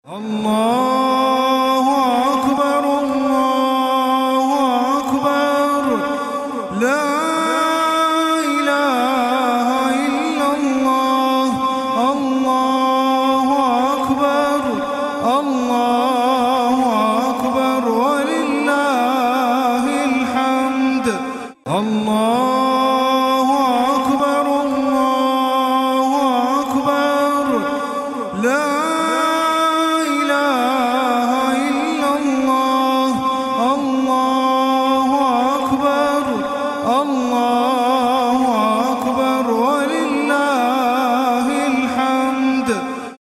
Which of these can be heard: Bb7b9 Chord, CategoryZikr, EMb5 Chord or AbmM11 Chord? CategoryZikr